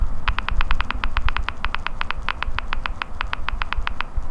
Play, download and share texting typing original sound button!!!!